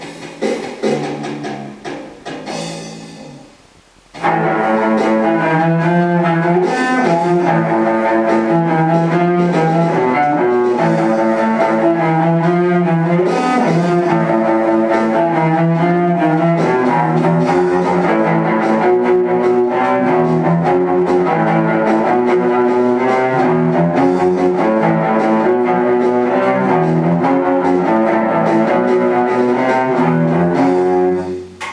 violoncelle :)